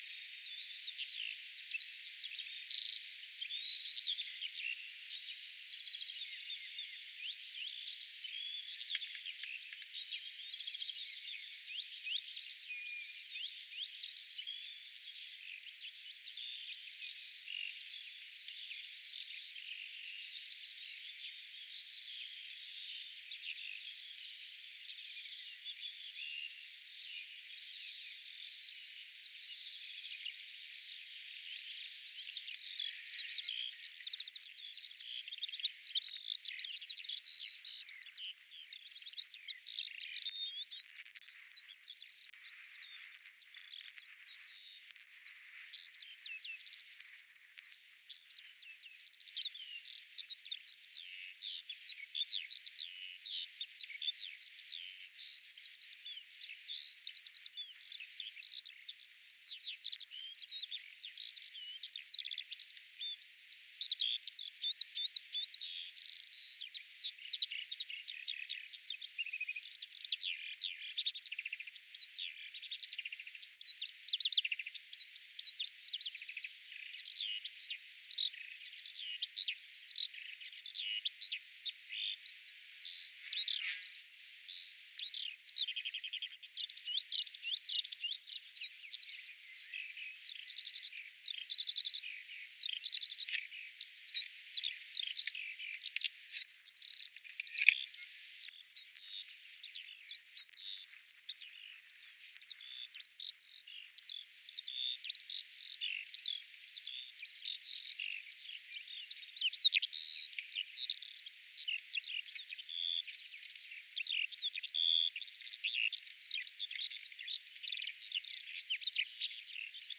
kiurut laulavat
kiurut_laulavat.mp3